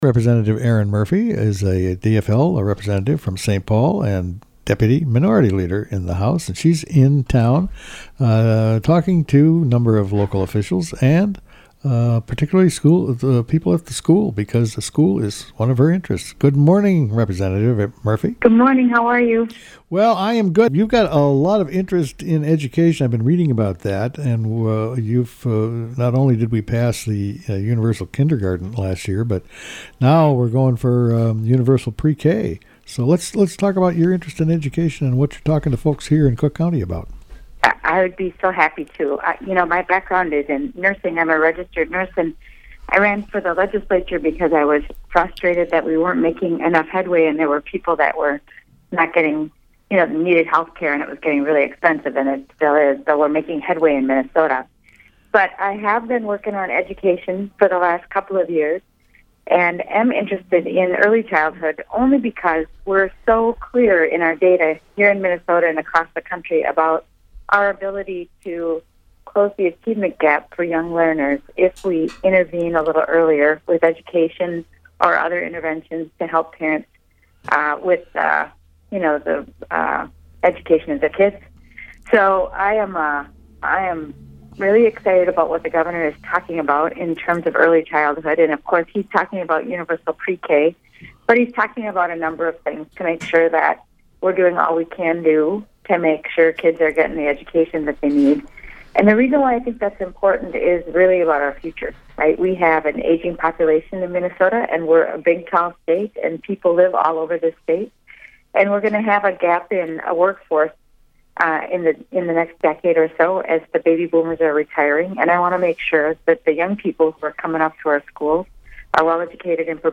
State budget issues discussed by Rep. Erin Murphy